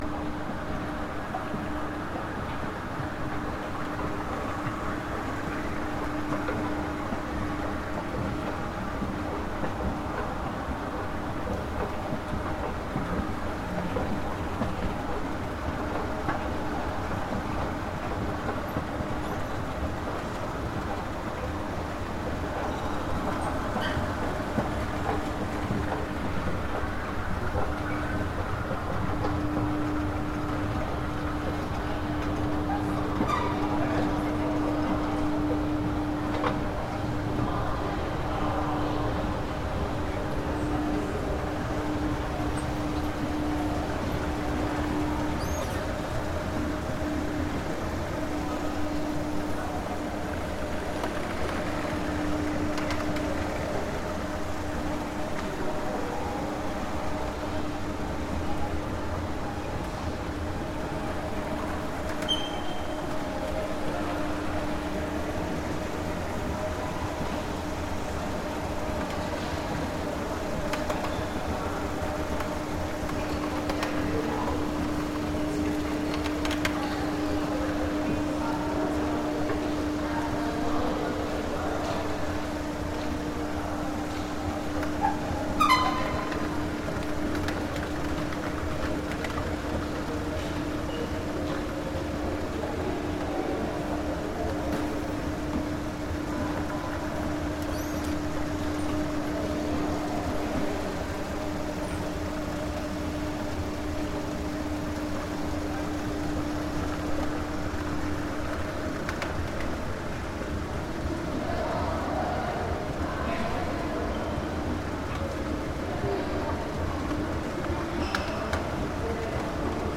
An elevator in Wagner metro station, Milan